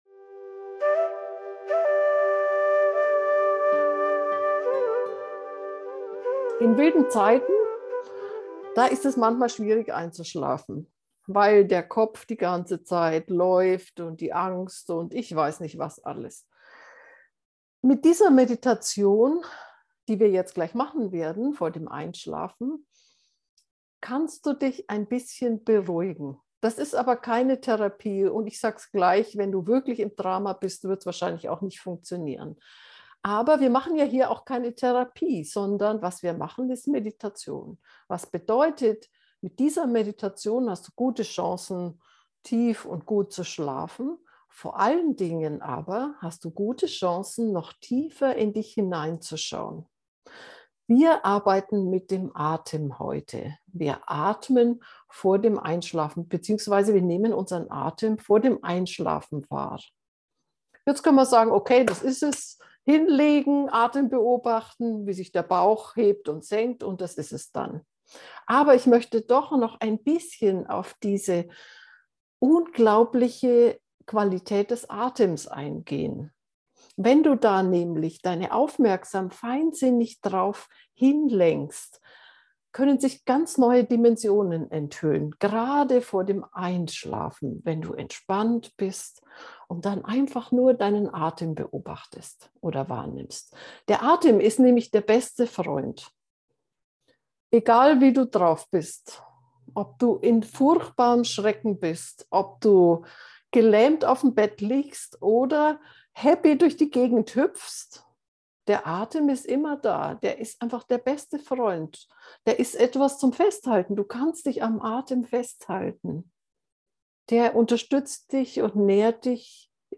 Folge 142: Sanfte Atemmeditation zum Einschlafen - FindYourNose
atemmeditation-zum-einschlafen.mp3